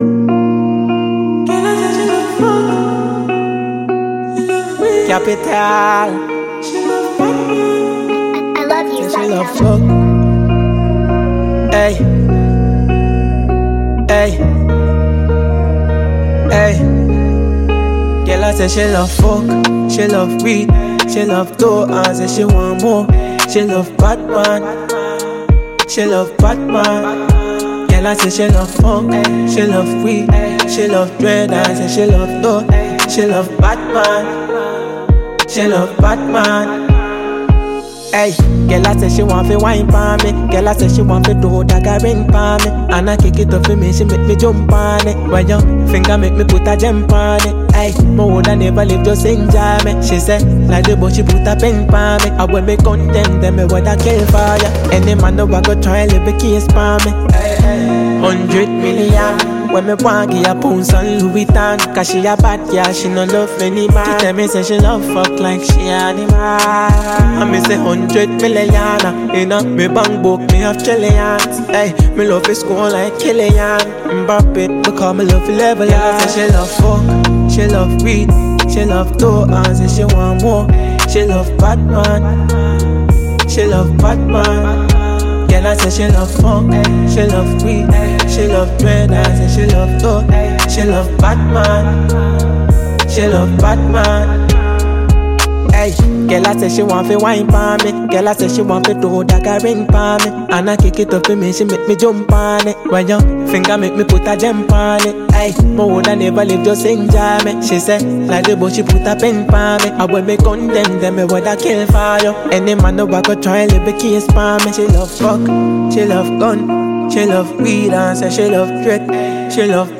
Ghanaian reggae dancehall